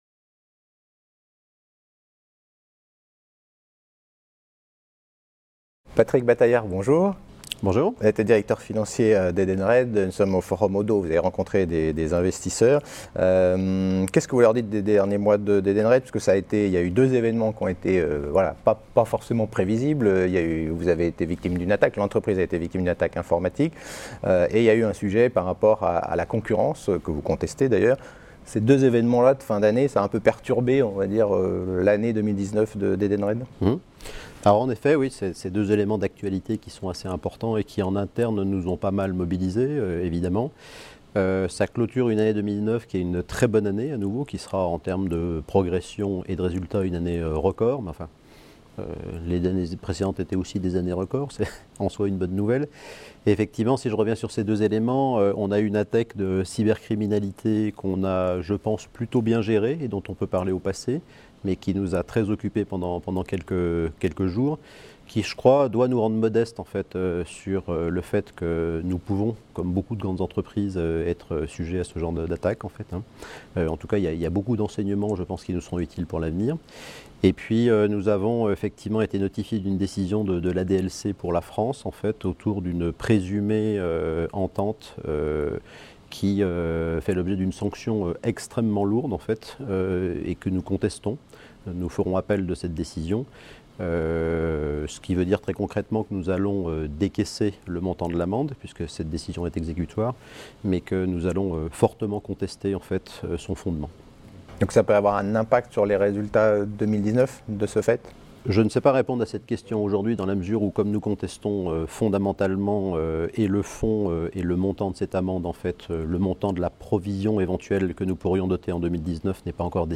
La Web Tv a rencontré les dirigeants au Oddo Forum qui s'est tenu à Lyon le 10 et le 11 janvier 2020